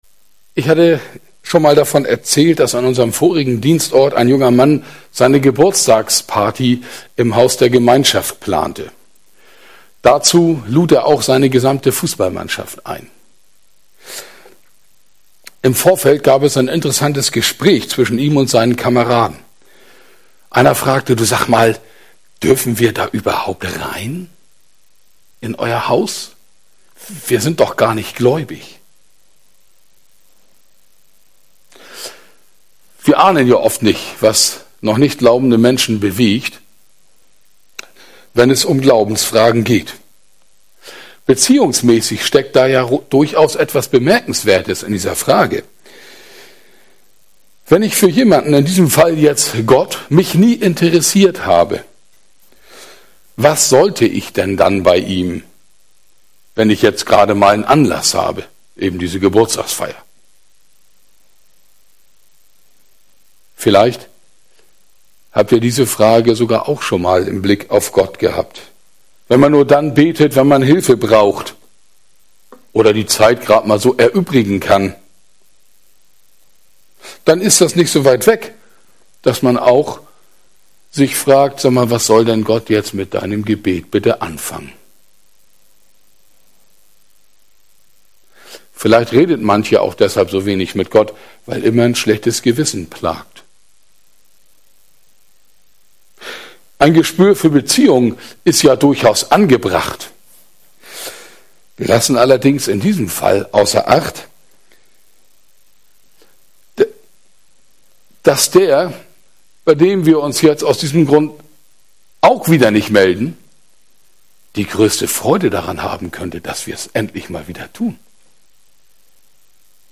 Predigten | Gemeinschaft in der Evangelischen Kirche
Gottesdienst am 6. Juni 2021